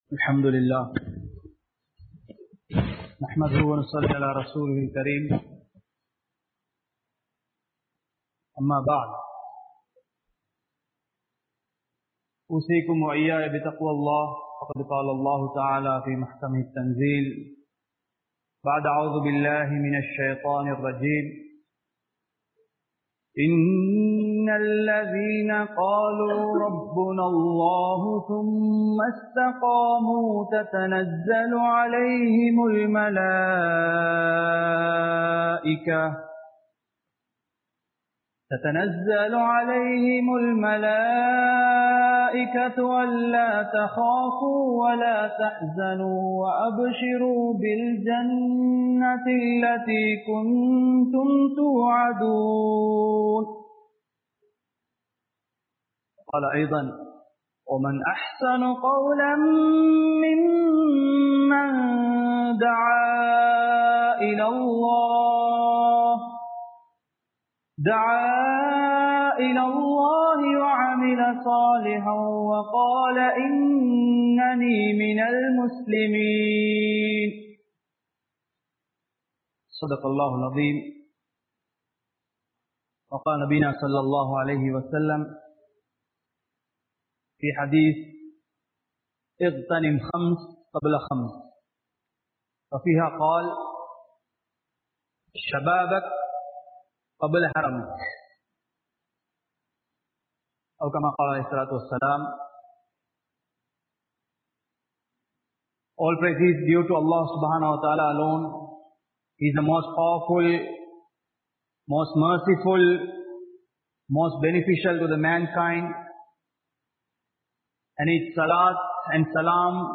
The Role of Muslim Youth | Audio Bayans | All Ceylon Muslim Youth Community | Addalaichenai